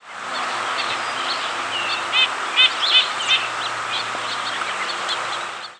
Red-breasted Nuthatch diurnal flight calls
Initial series from perched bird then calls from bird in flight. Ruby-crowned Kinglet and several warbler species calling in the background.